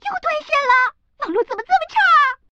Index of /client/common_mahjong_tianjin/mahjongwuqing/update/1168/res/sfx/doudizhu/woman/